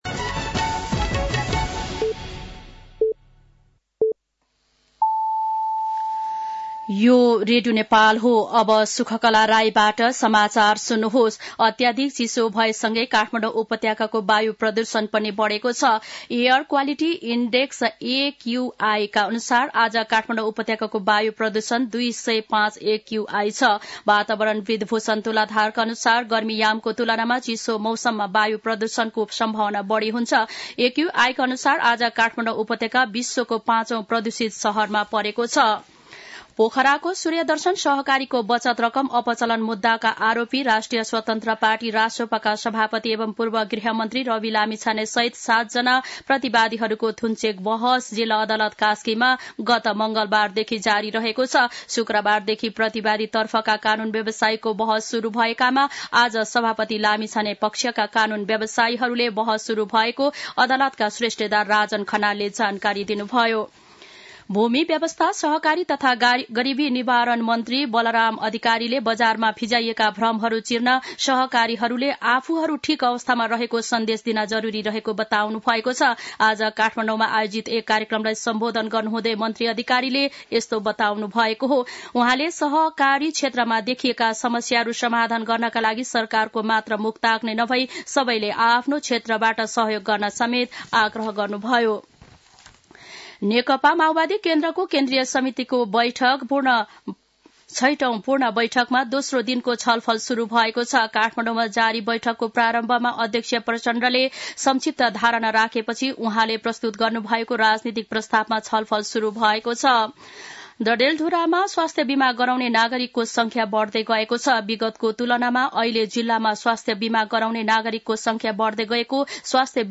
दिउँसो ४ बजेको नेपाली समाचार : २३ पुष , २०८१
4-pm-news-.mp3